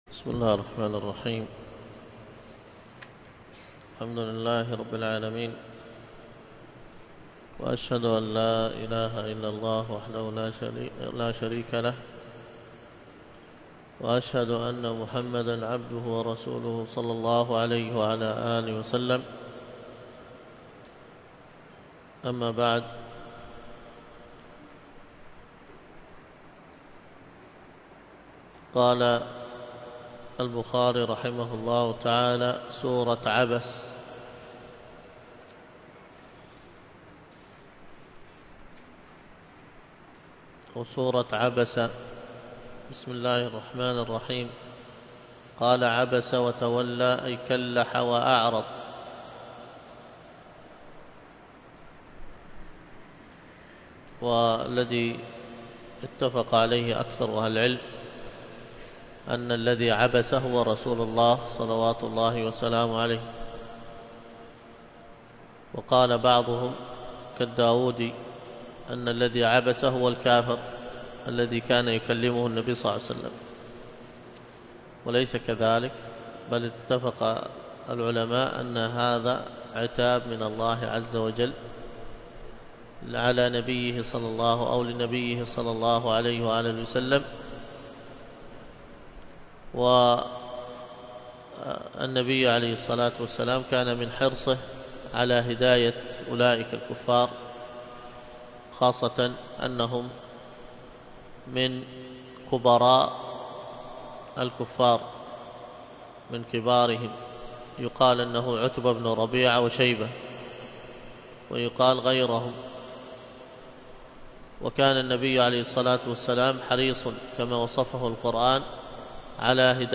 الدرس في شرح كتاب فن التجويد 20، الدرس العشرون:من(الفصل السابع:صفات الحروف...4ـ الرخاوة..ماعدا حروف الشدة والتوسط ).